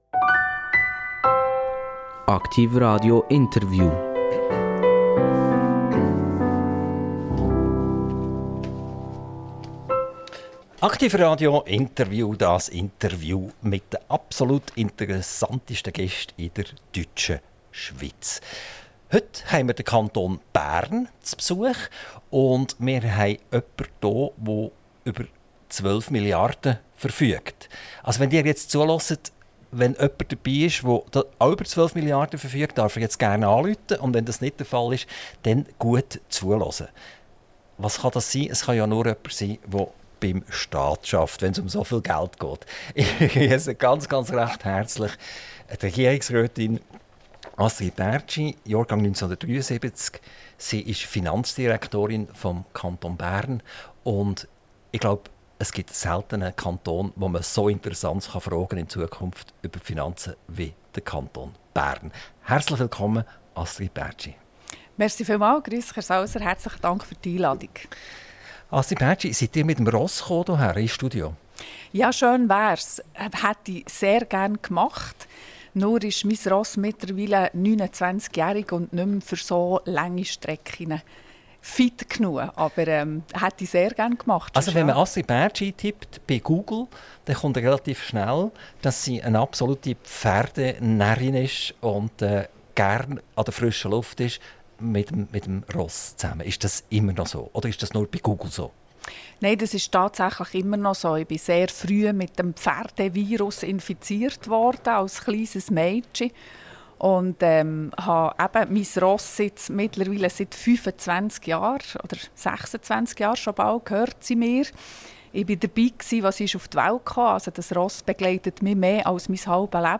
INTERVIEW - Astrid Bärtschi - 28.06.2024 ~ AKTIV RADIO Podcast